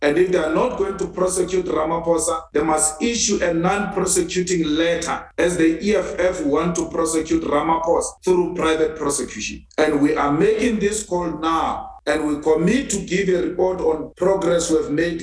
Speaking during the commemoration of the Marikana massacre on Monday, Malema says action must be taken against anyone implicated in the killings.